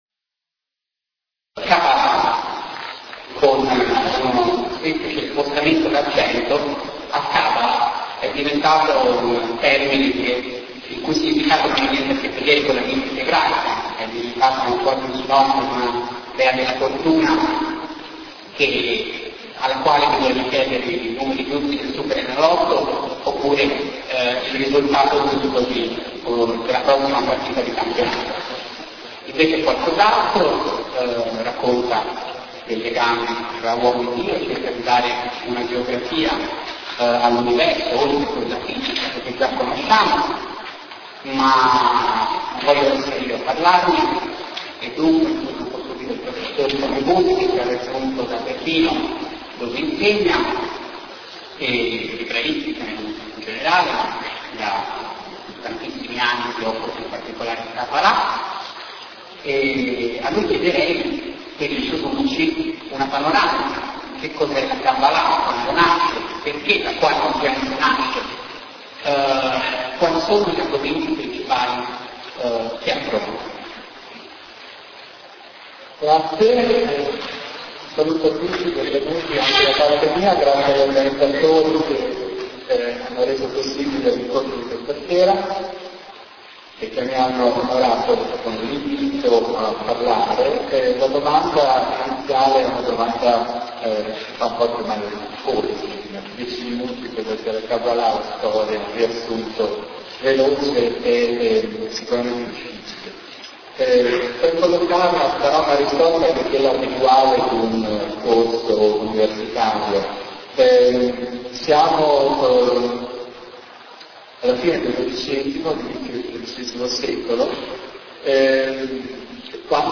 Palazzo della Cultura
dibattito